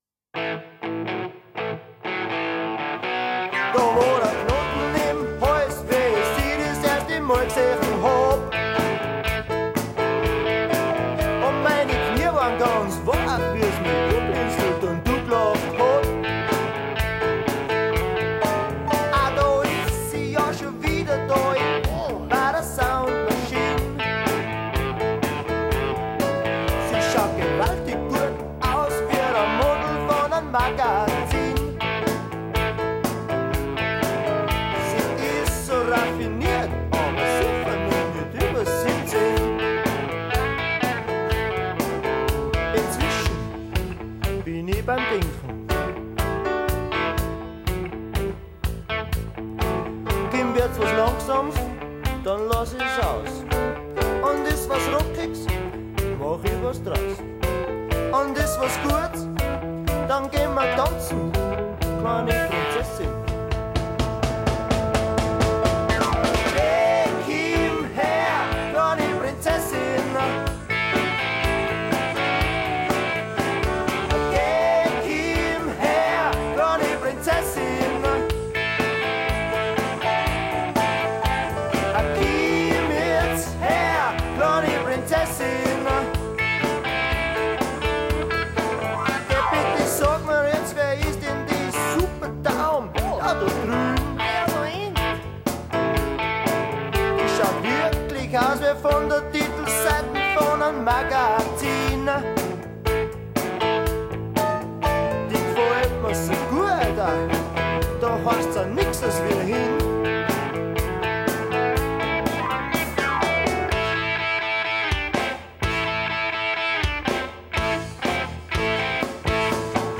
wird hier im Dialekt gesungen.
Klavier